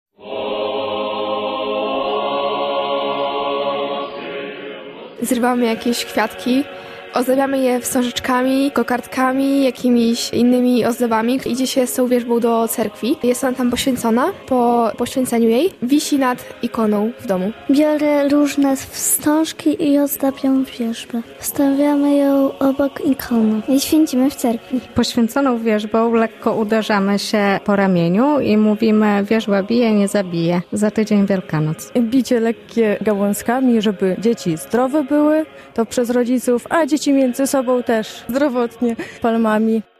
Niedziela Palmowa w Cerkwi prawosławnej - relacja